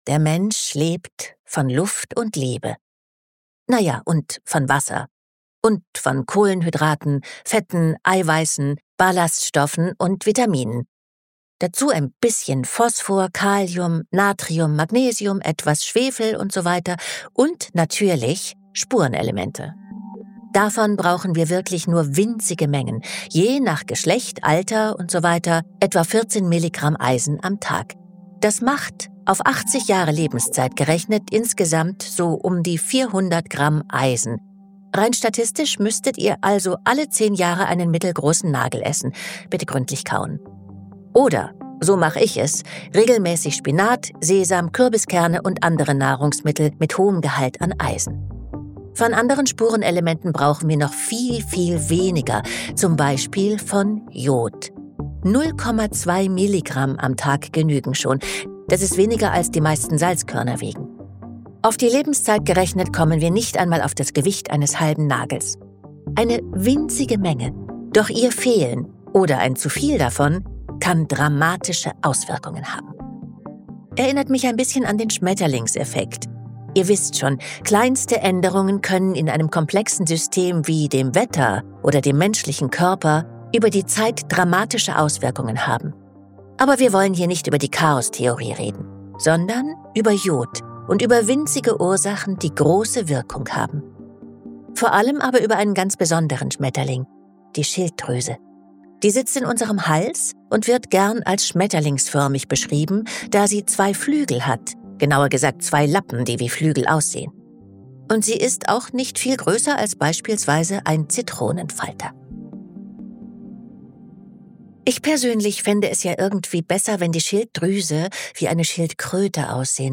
Von der allerersten Hormontherapie der Welt und makabren Sehenswürdigkeiten erzählt Andrea Sawatzki in dieser Folge.